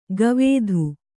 ♪ gavēdhu